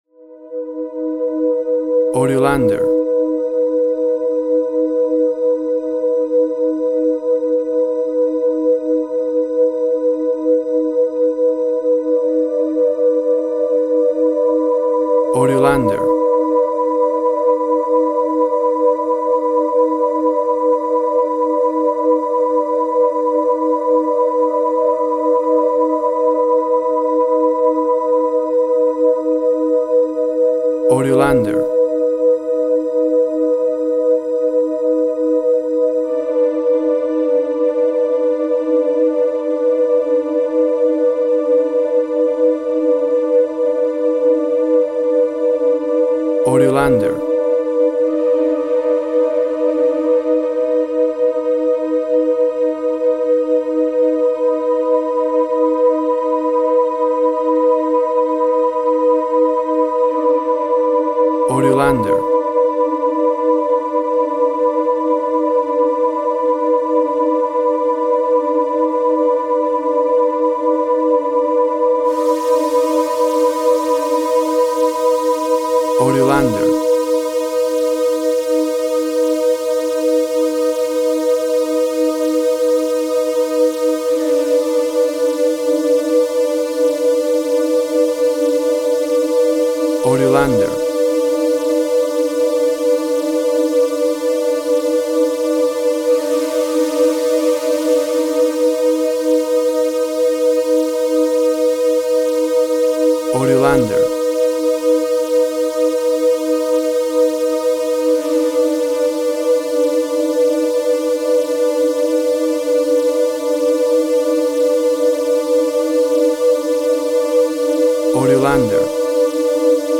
A deadly dark scary witchcraft music theme.
WAV Sample Rate 16-Bit Stereo, 44.1 kHz